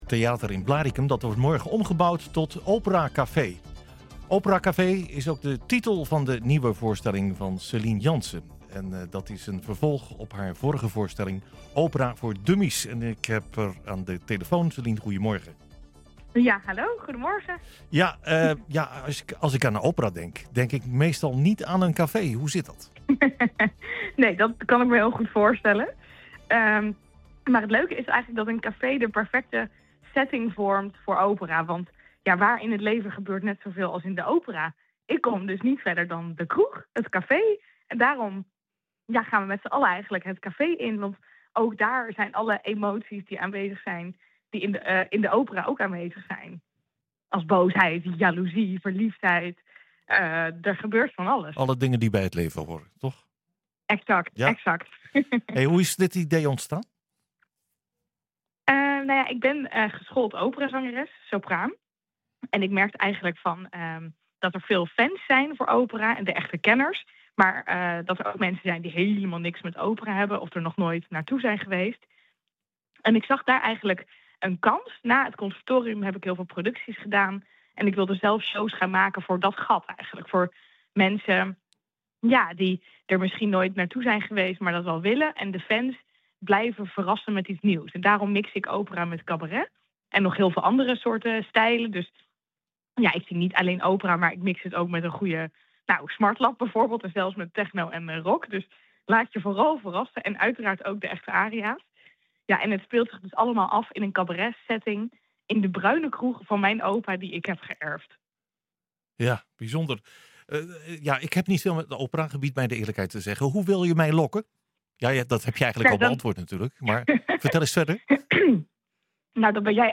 Ze is aan de telefoon.